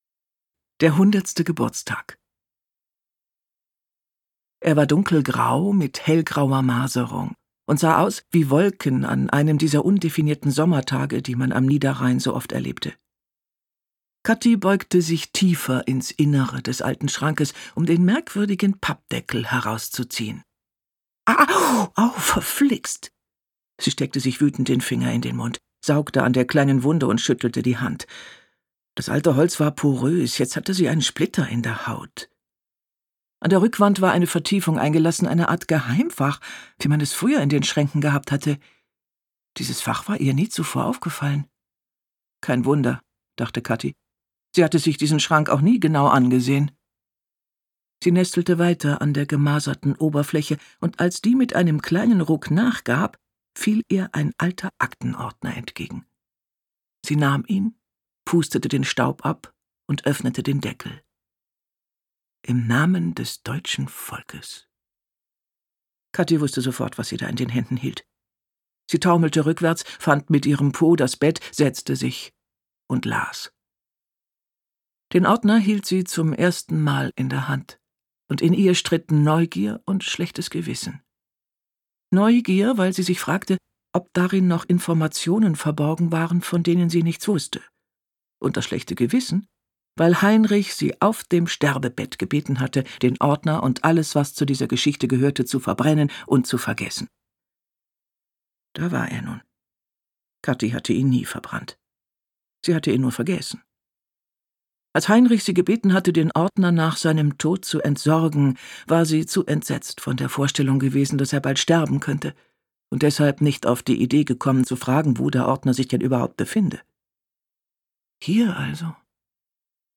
Ob klassische Stoffe, Kinderbuch oder blutige Krimis – mit ihrer unverkennbaren vollen Stimme macht sie jede Lesung zu einem spannenden Ereignis.